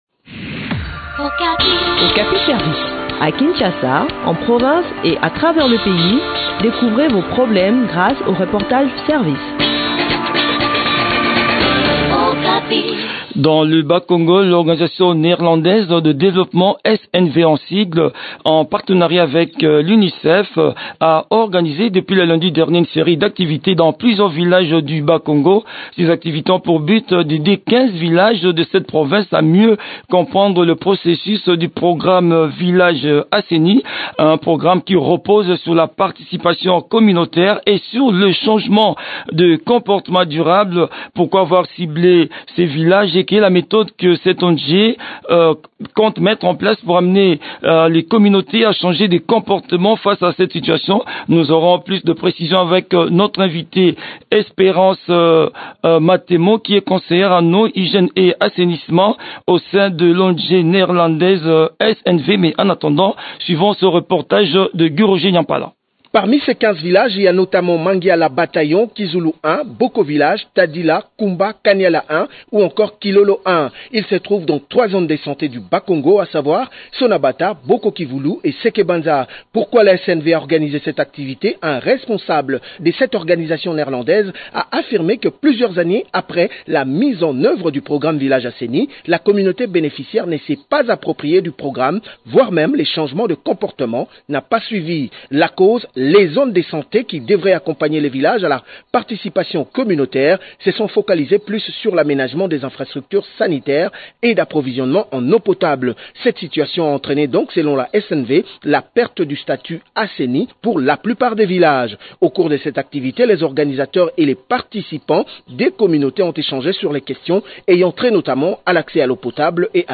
Le point sur l’exécution de ce projet dans cet entretien